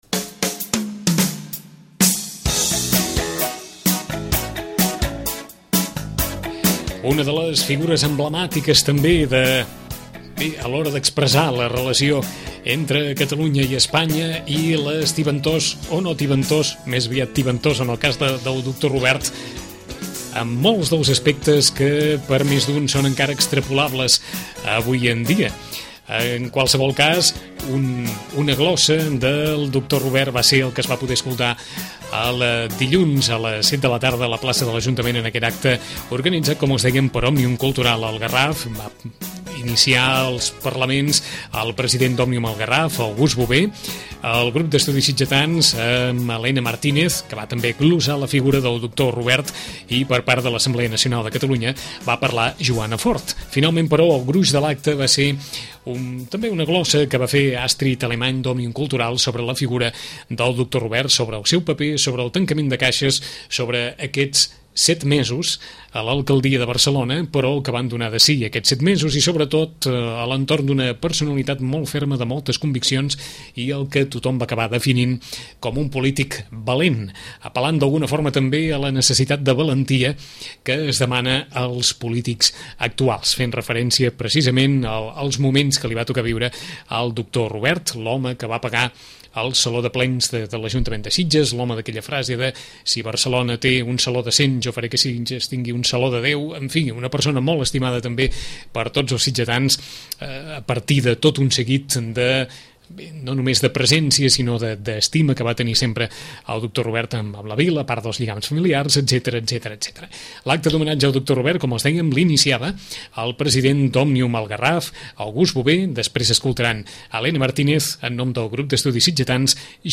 Us oferim l’acte d’homenatge al doctor Bartomeu Robert, que organitzaren la delegació del Garraf d’Òmnium cultural, el Grup d’Estudis Sitgetans i la delegació a Sitges de l’Assemblea Nacional Catalana.